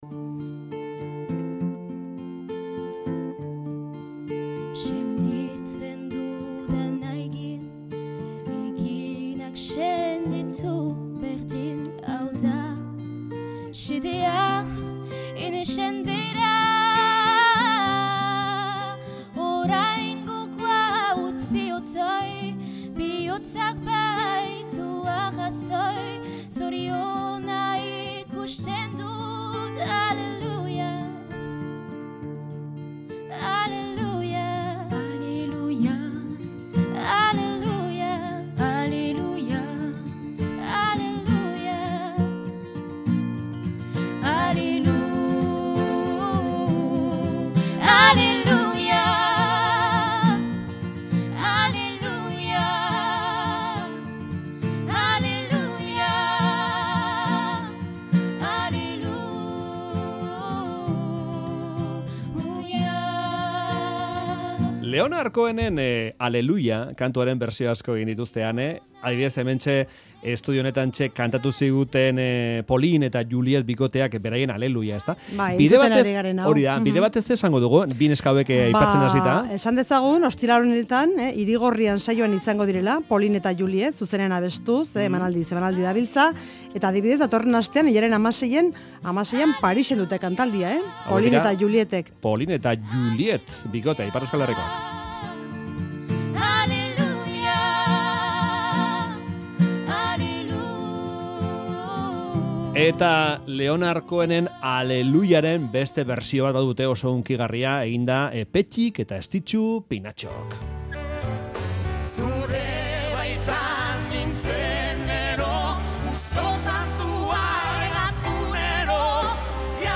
Bi musikari gazteekin hitz egin dugu Faktorian.